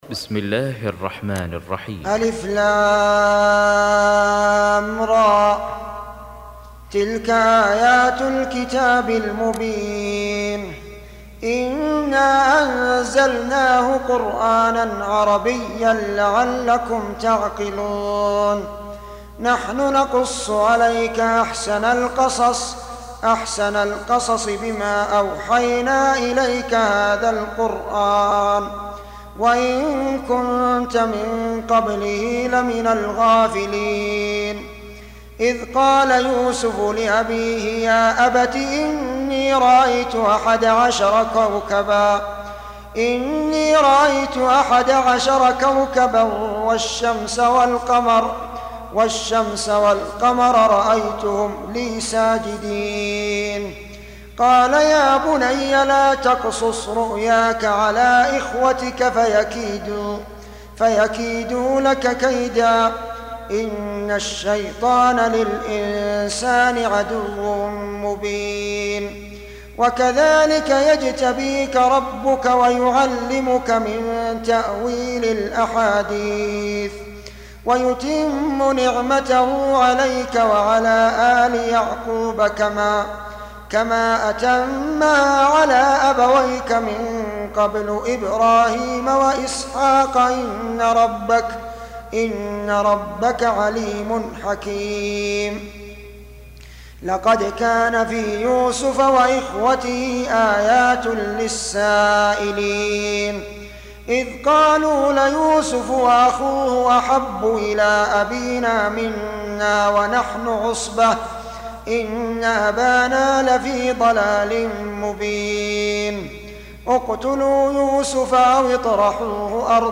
Surah Sequence تتابع السورة Download Surah حمّل السورة Reciting Murattalah Audio for 12. Surah Y�suf سورة يوسف N.B *Surah Includes Al-Basmalah Reciters Sequents تتابع التلاوات Reciters Repeats تكرار التلاوات